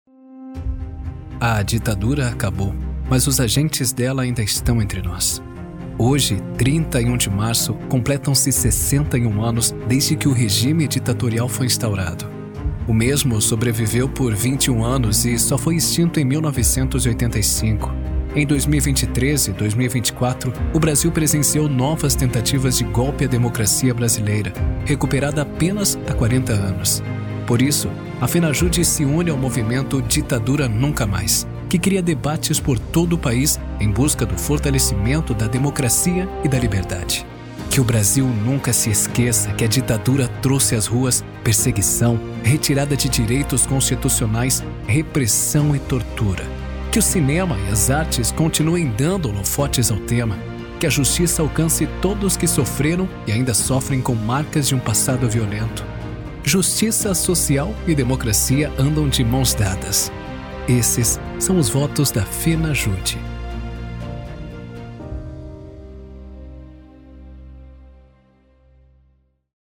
VOZ PARA VÍDEO DE HISTÓRIA/DOCUMENTAL: